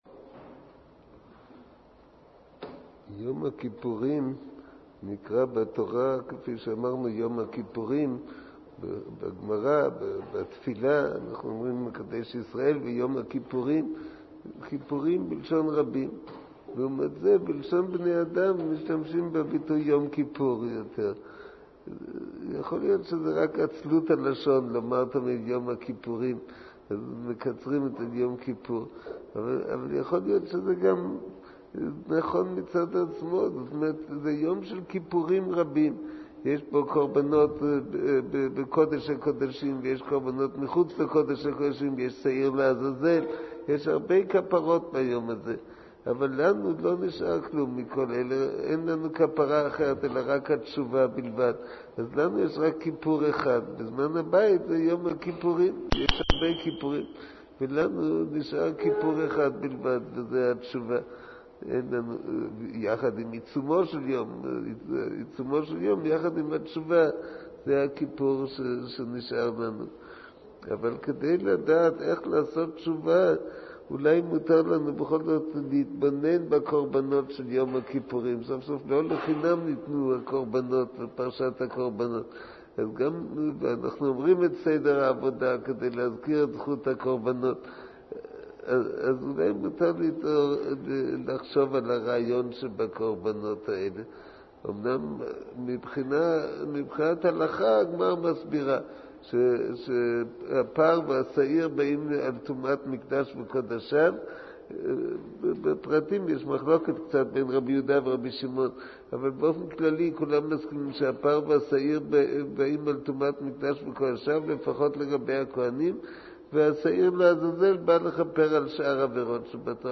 שיחה לפרשת האזינו
מעביר השיעור: מו"ר הרב אביגדר נבנצל